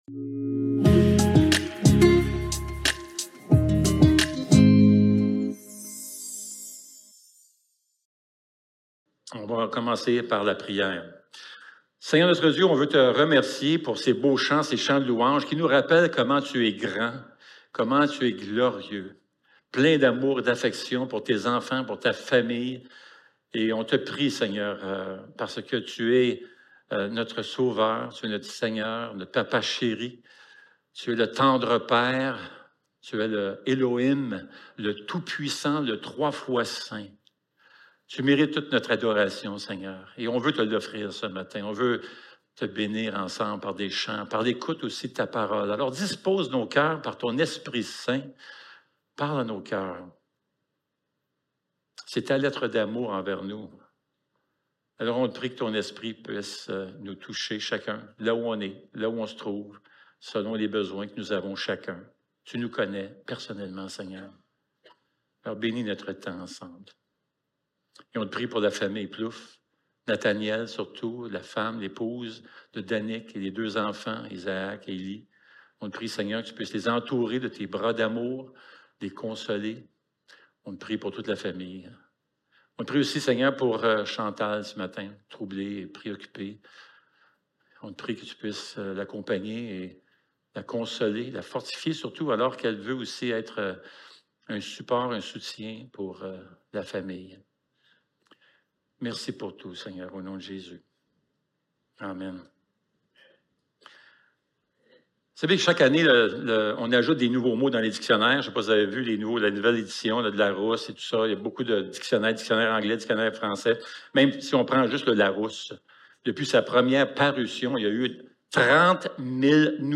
Éphésiens 1.3-8 Service Type: Célébration dimanche matin Éveil à la Grâce #1 Croire en la grâce est une chose